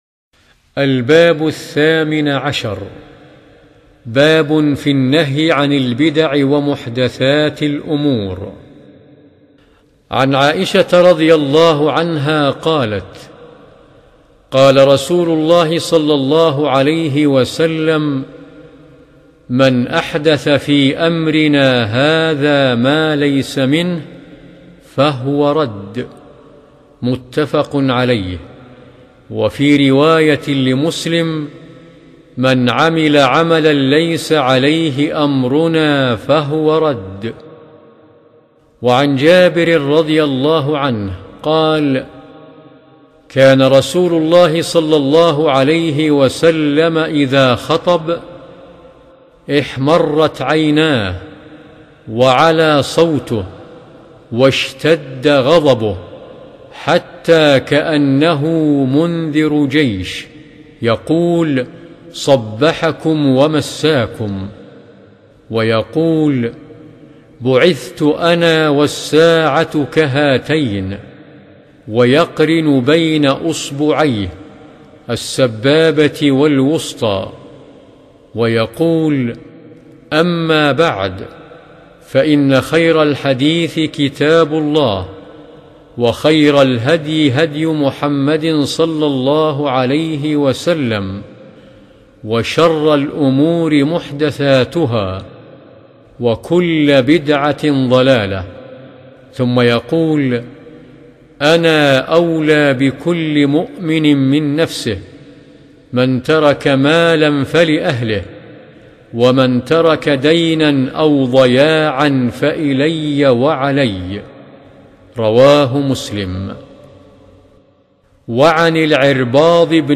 رياض الصالحين(باب النهي عن البدع)قراءة